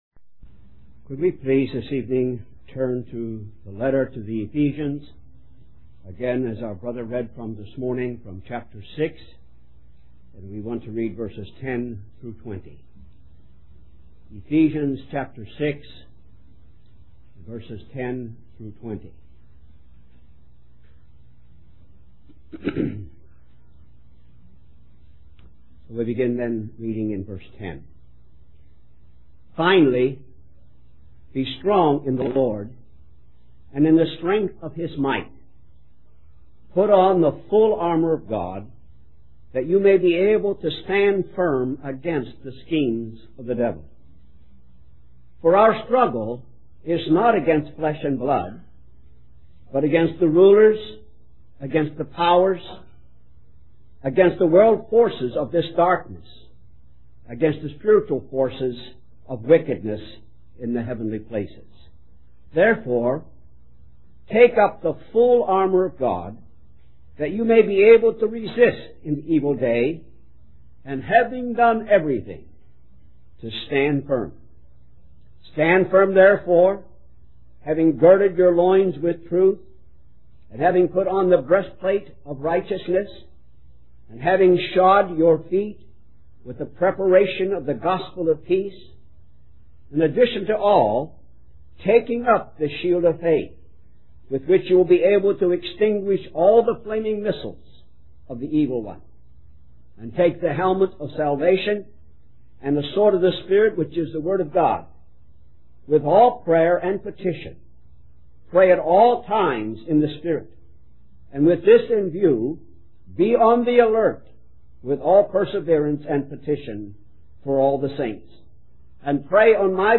Harvey Cedars Conference
Message